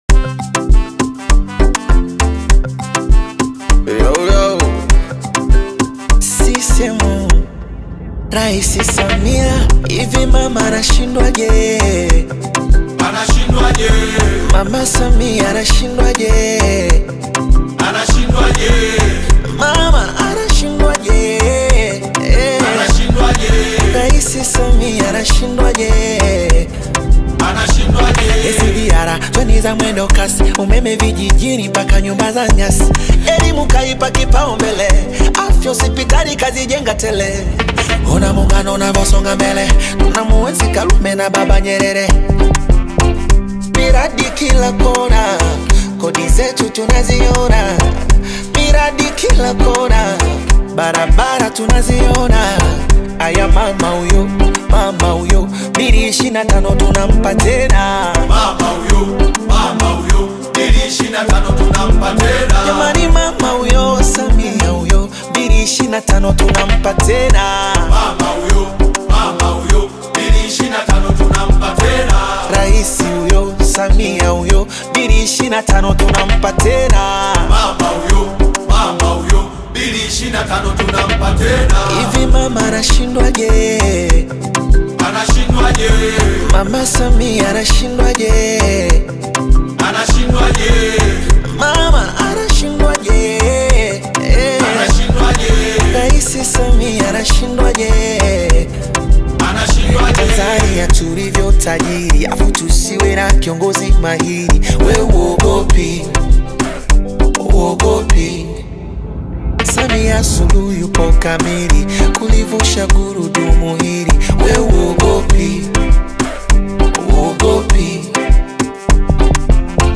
poignant Afro-pop/Bongo Flava single
Genre: Bongo Flava